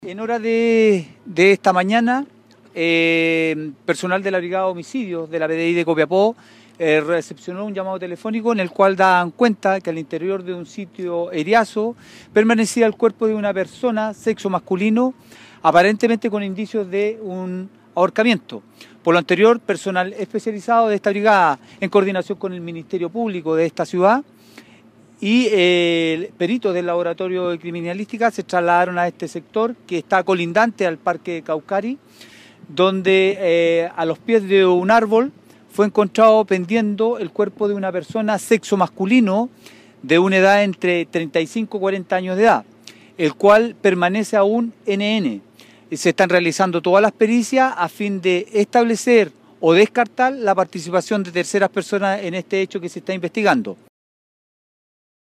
entregó un reporte del hecho, en el sitio del suceso